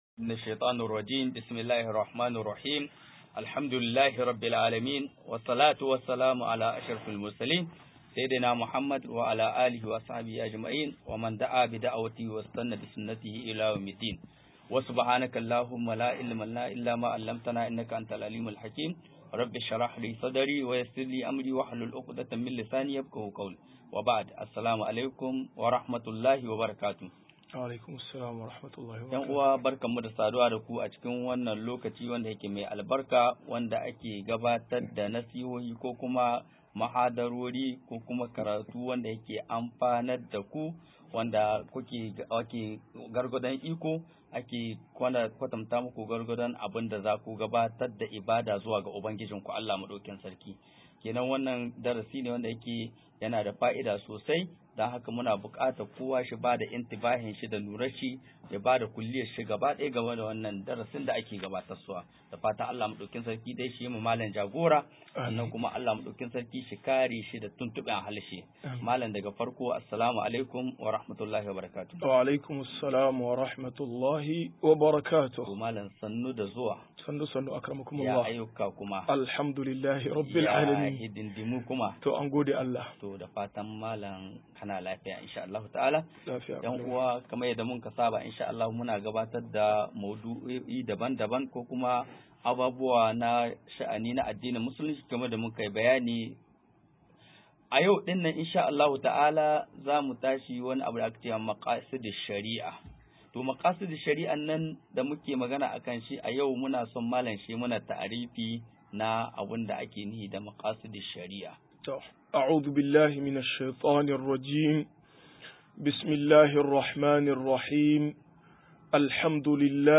162-Ilimin Makaasid Shari a 1 - MUHADARA